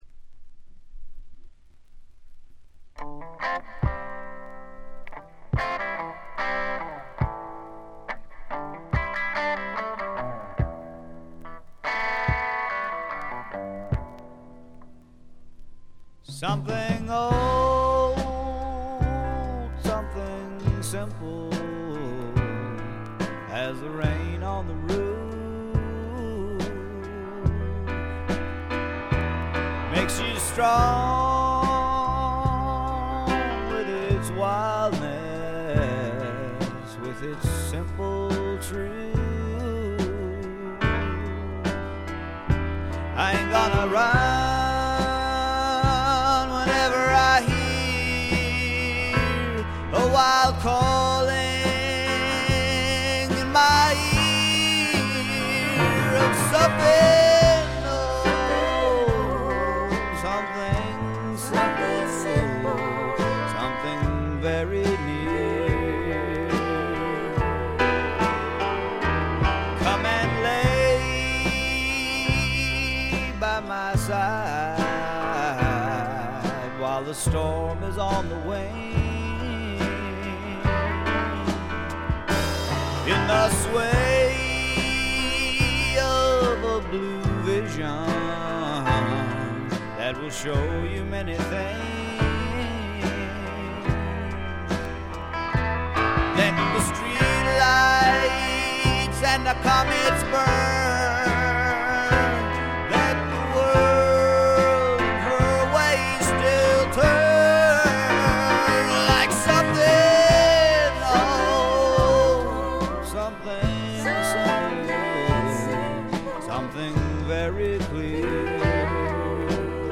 ごくわずかなノイズ感のみ。
聴くものの心をわしづかみにするような渋みのある深いヴォーカルは一度聴いたら忘れられません。
試聴曲は現品からの取り込み音源です。